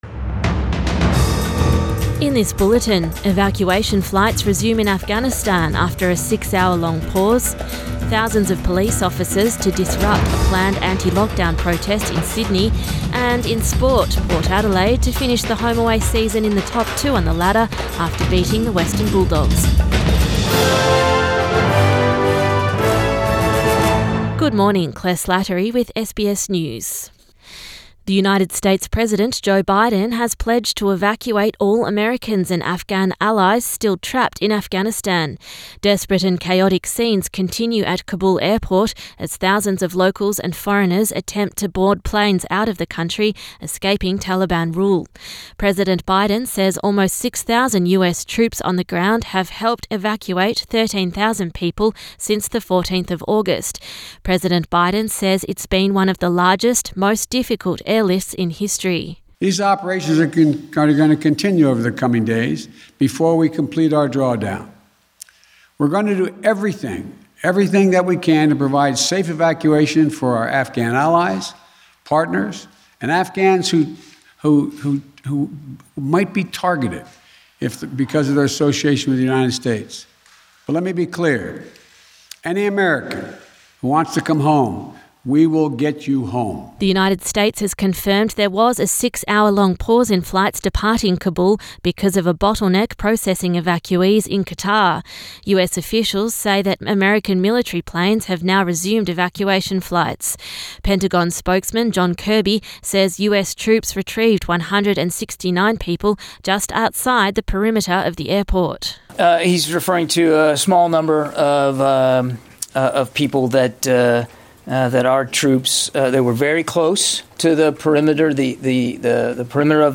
AM bulletin 21 August 2021